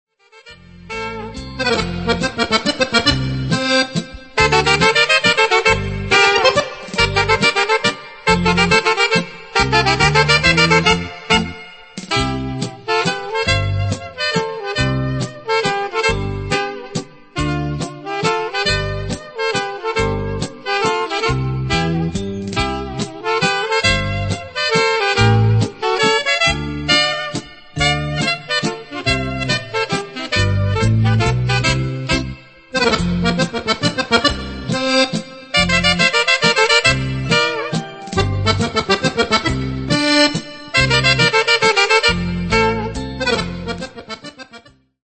mazurca